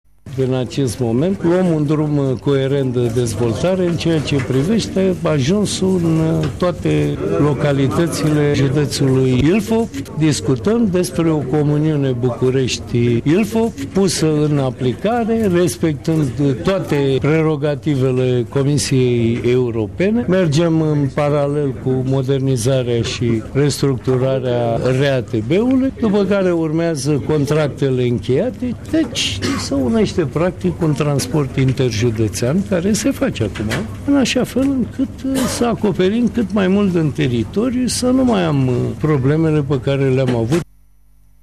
Acest lucru este posibil doar după ce planul de mobilitate, la care se lucrează de trei ani, va fi aprobat de Consiliile Municipiului București și județului Ilfov, spune Primarul Capitalei Sorin Oprescu: